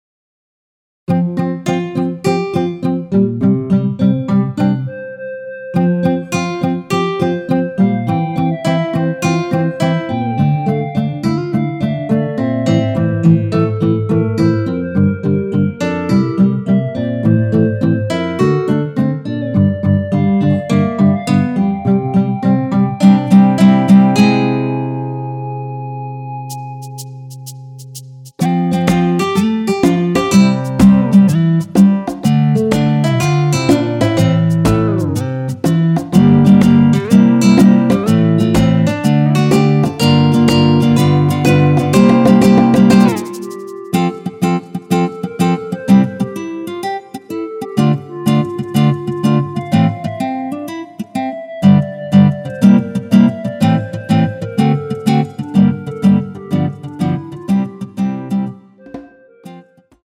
전주 없이 시작 하는곡이라 노래 하시기 편하게 전주 2마디 많들어 놓았습니다.(미리듣기 확인)
원키에서(+1)올린 멜로디 포함된 MR입니다.(미리듣기 확인)
앞부분30초, 뒷부분30초씩 편집해서 올려 드리고 있습니다.